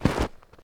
pedology_snow_footstep.2.ogg